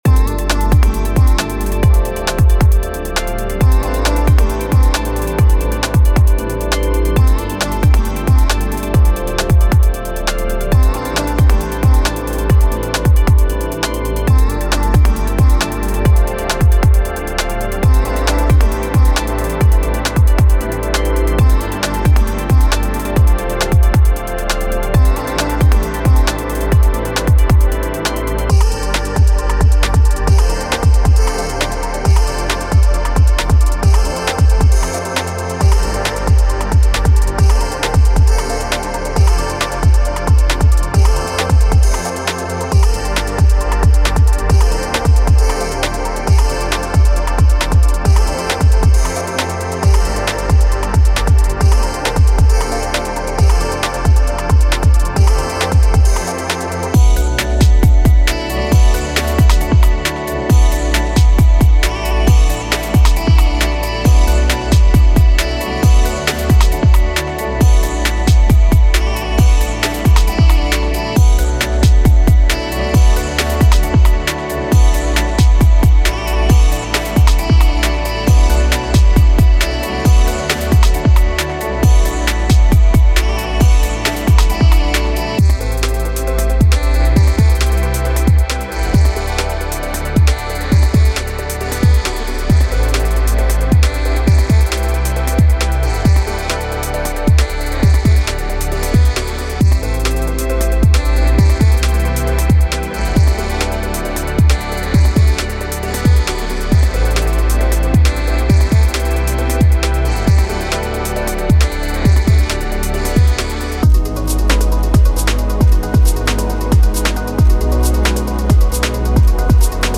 Designed for producers seeking to infuse their tracks with a blend of moody atmospheres and forward-thinking rhythms, this pack is a must-have for your sound library.
15 Bass Loops: Deep, resonant basslines that provide a solid foundation for your tracks.
30 Drum Loops: A diverse collection of drum loops that range from crisp, minimal beats to complex, broken rhythms.
30 Synth Loops: Atmospheric synths and haunting melodies that will add an ethereal quality to your tracks.
From ambient sweeps to intricate glitches, these sounds will add an extra layer of professionalism to your productions.
5 Vocal Loops: Soulful and emotive vocal snippets that bring a human touch to your music.